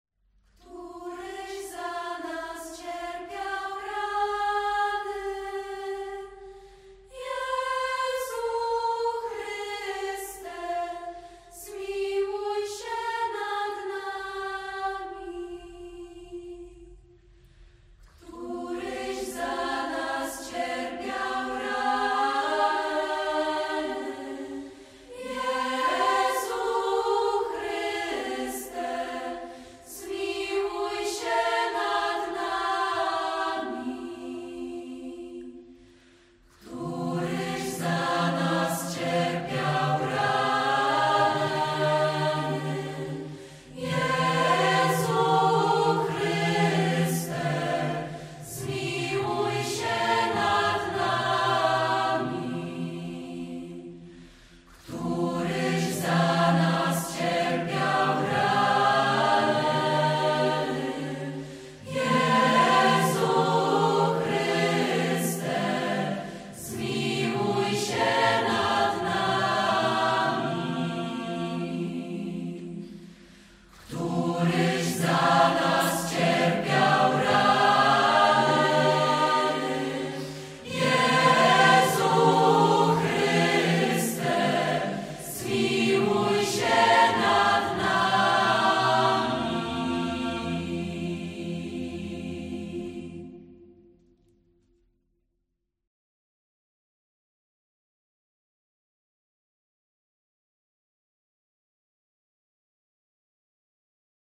Rozważania Drogi Krzyżowej – całość audio.